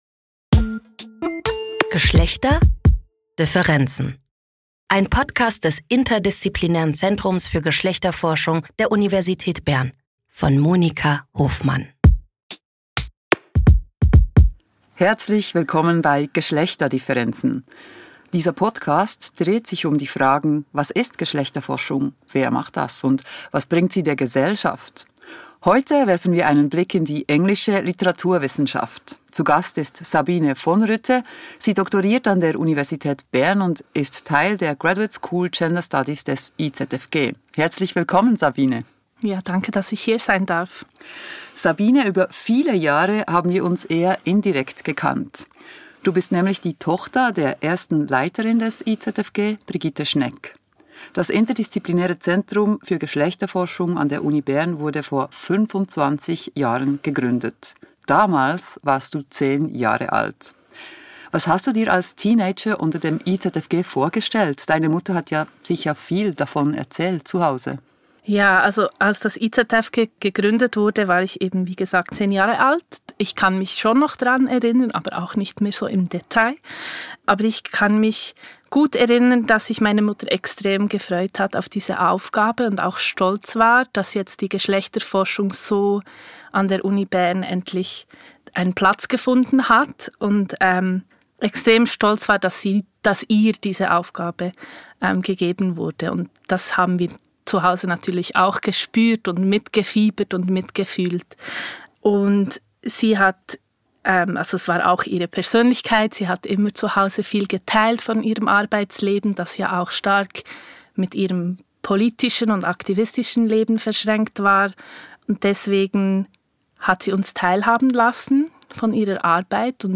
Im Gespräch erzählt sie aber nicht nur von ihrer Forschung, sondern auch von der Arbeit als Studienfachberaterin.